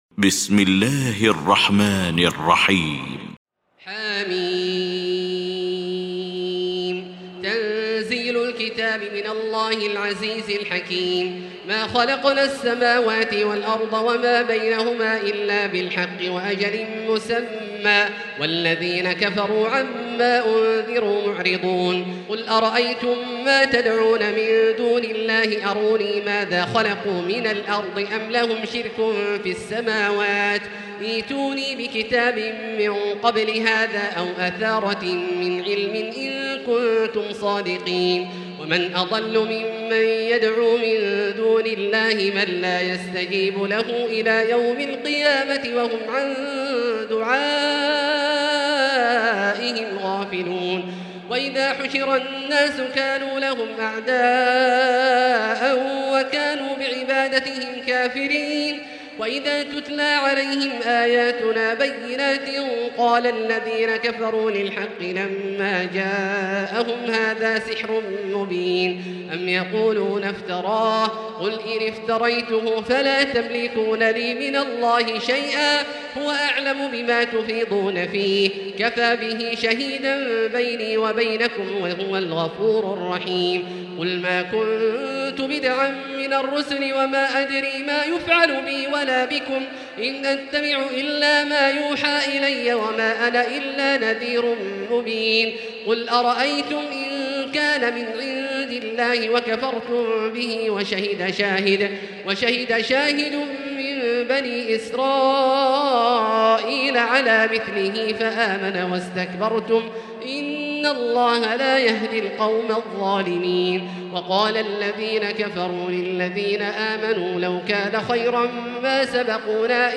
المكان: المسجد الحرام الشيخ: فضيلة الشيخ عبدالله الجهني فضيلة الشيخ عبدالله الجهني الأحقاف